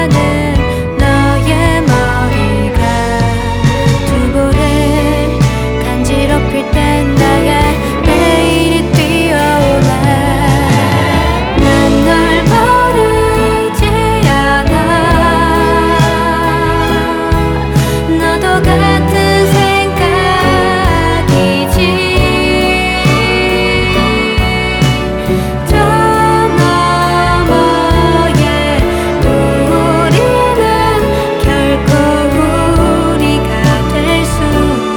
Rock Pop K-Pop
Жанр: Поп музыка / Рок